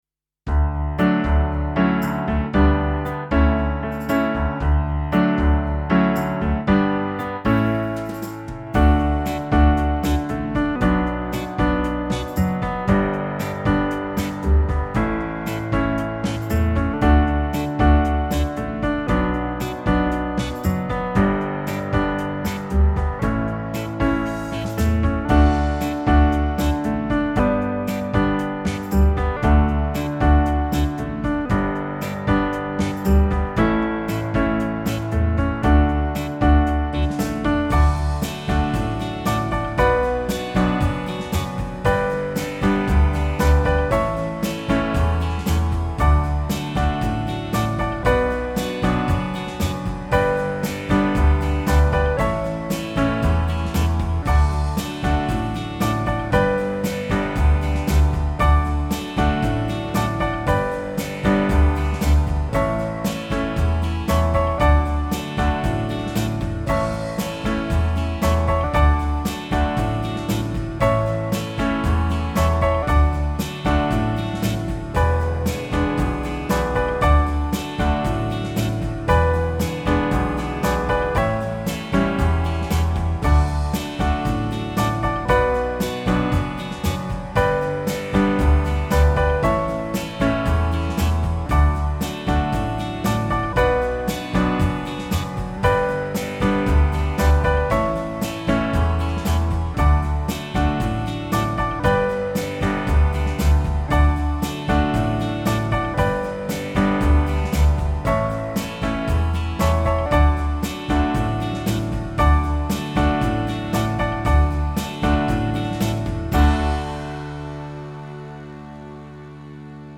Home > Music > Pop > Bright > Smooth > Laid Back